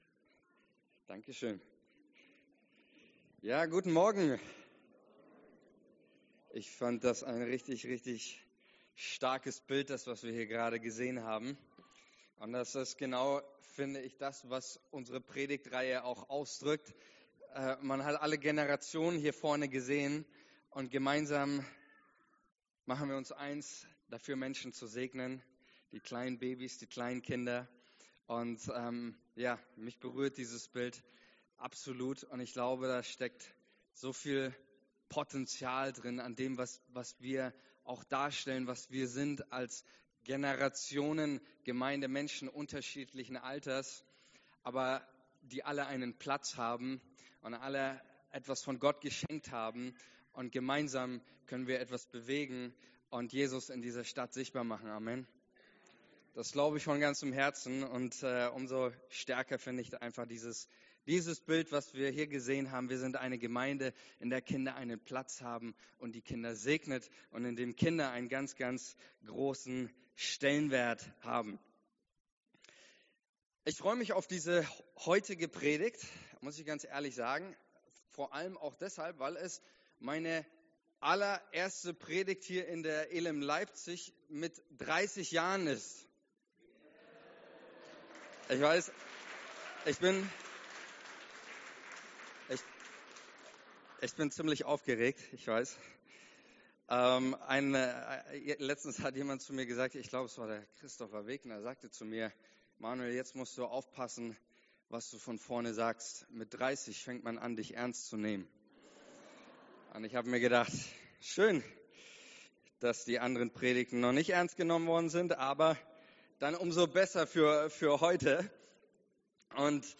11,49 MB Teil 3 der Predigtserie